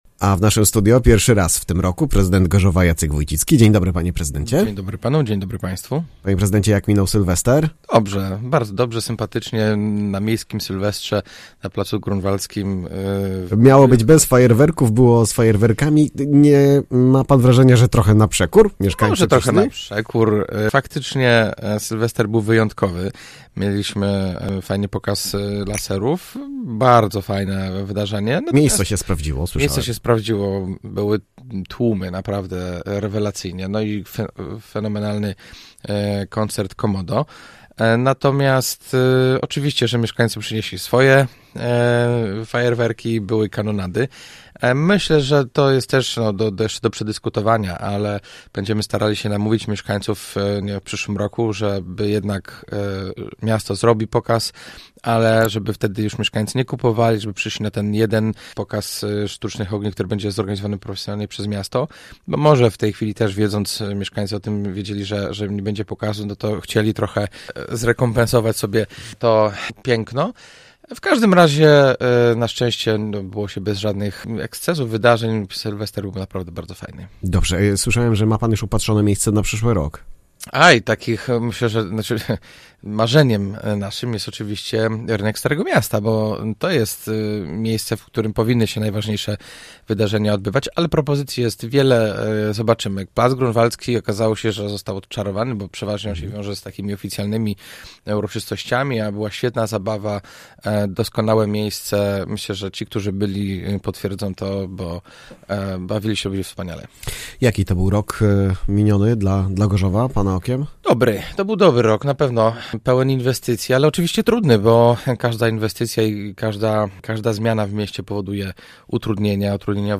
Gościem programu był prezydent Gorzowa Jacek Wójcicki.